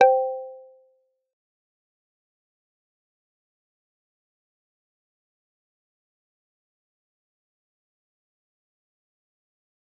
G_Kalimba-C5-f.wav